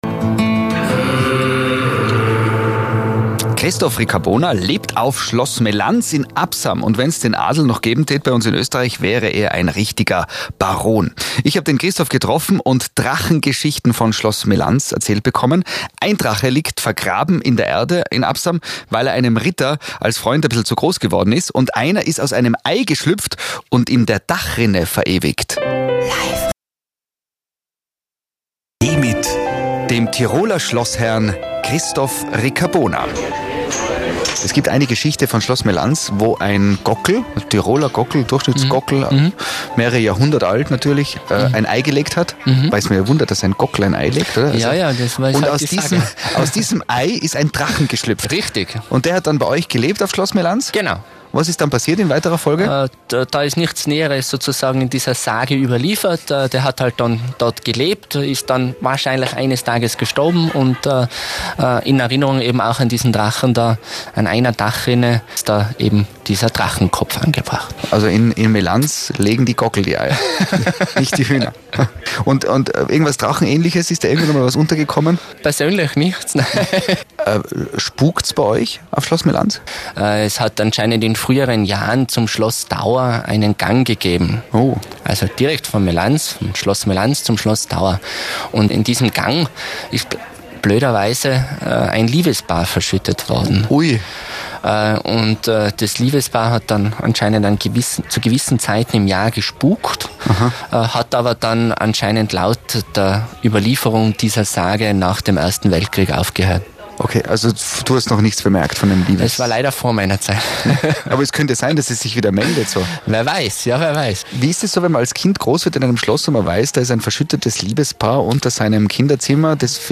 Am 09.02.2019 war ich im Life Radio Studio – hier gibt es das Gespräch zum Nachhören im mp3 Format: